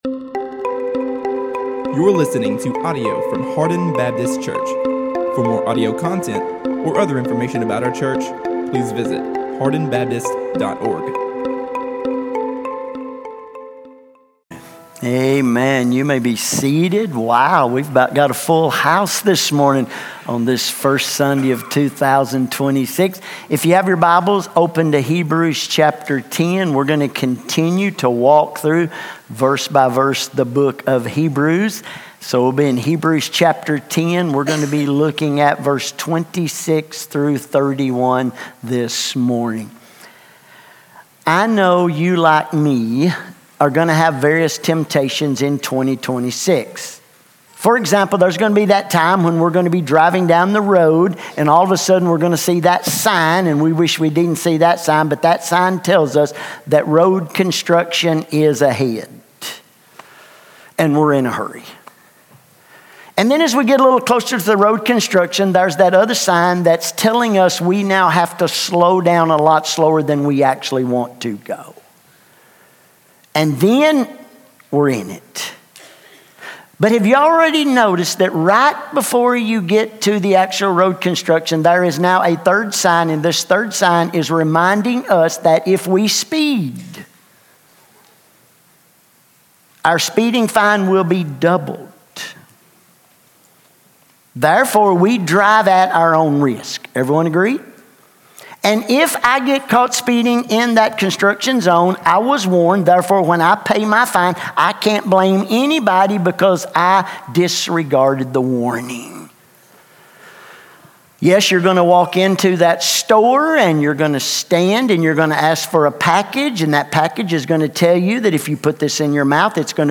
A message from the series "Hebrews 2025."